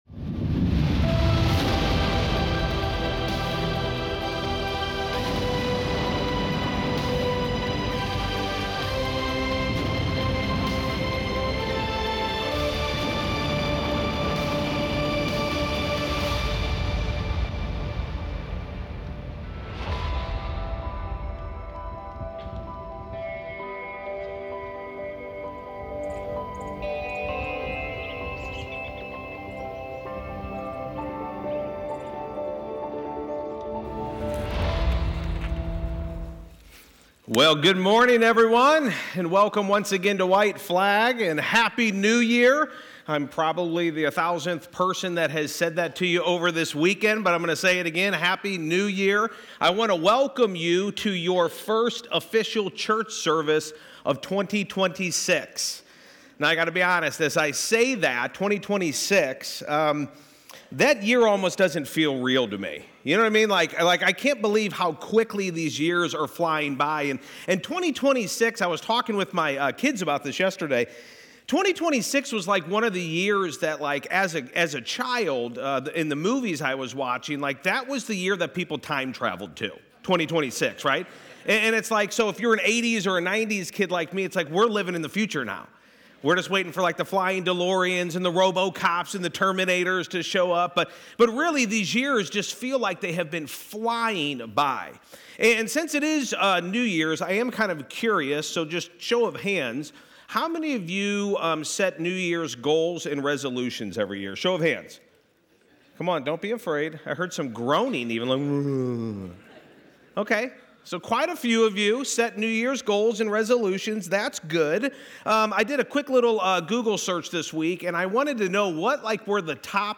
reset-week-2-2-peter-1-sermon.mp3